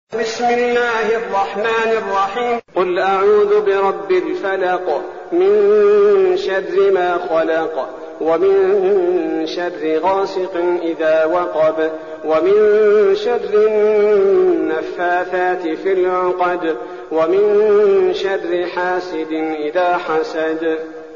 المكان: المسجد النبوي الشيخ: فضيلة الشيخ عبدالباري الثبيتي فضيلة الشيخ عبدالباري الثبيتي الفلق